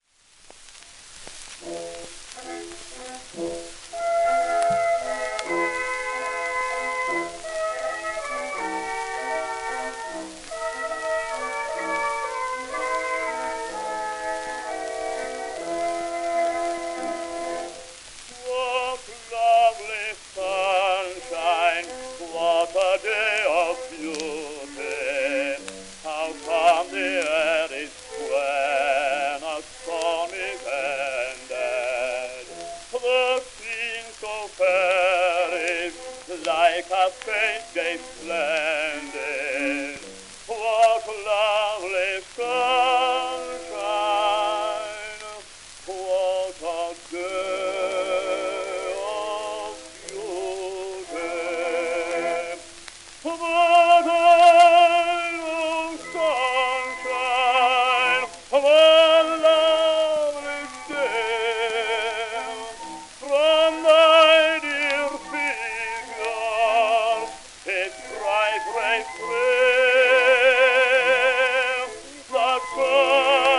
w/オーケストラ
盤質A- *小キズ,サーフェイスノイズ
1913年録音
旧 旧吹込みの略、電気録音以前の機械式録音盤（ラッパ吹込み）